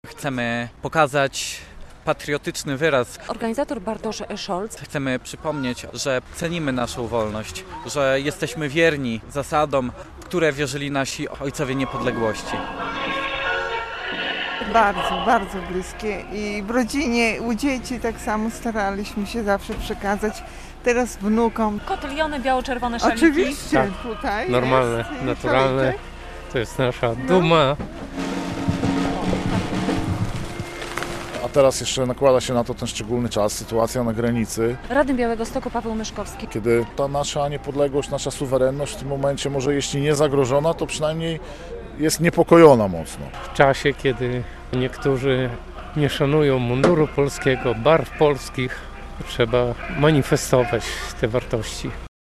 Około 200 osób wzięło udział w Białostockim Marsz Niepodległości - relacja